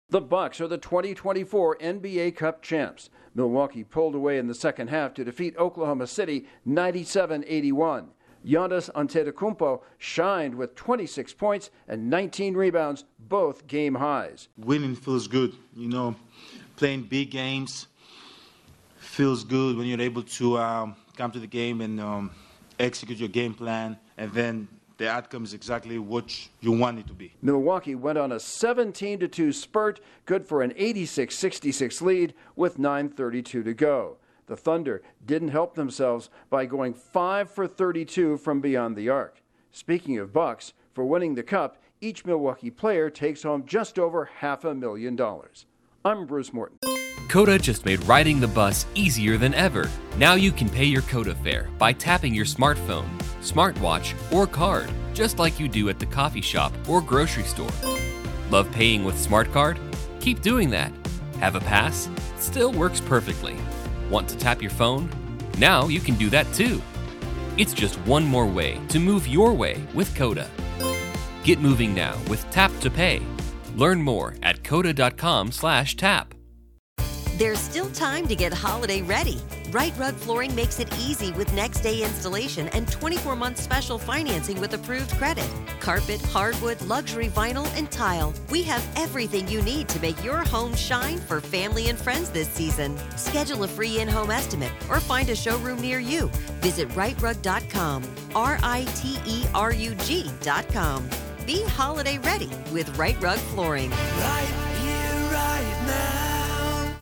The Bucks pick up an in-season title. Correspondent